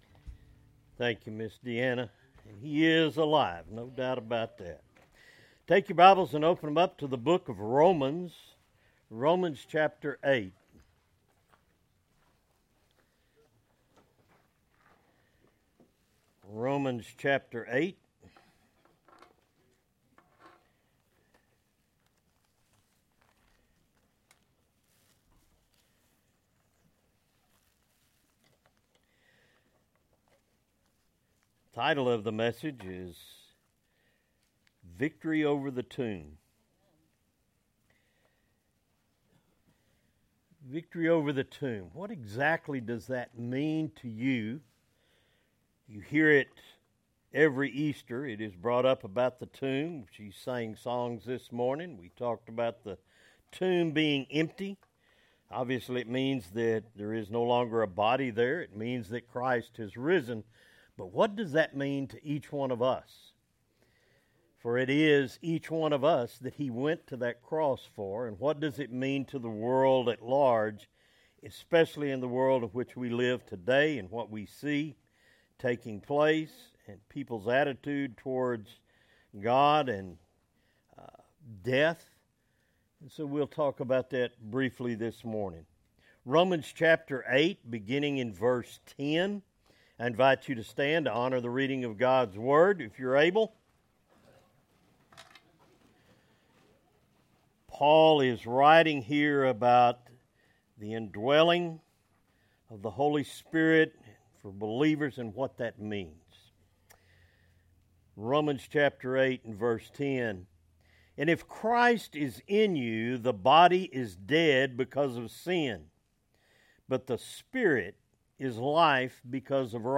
Message Sunday 4-20-25